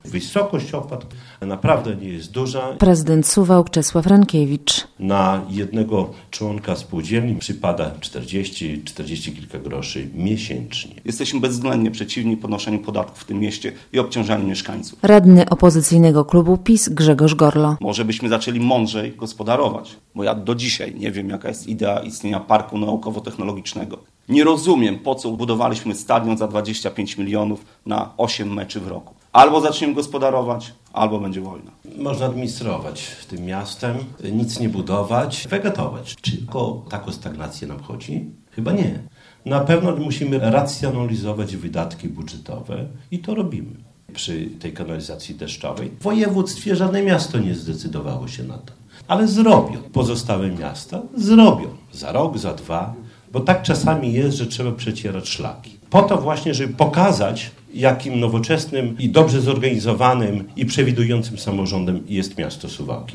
Kontrowersyjne "opłaty deszczowe" - relacja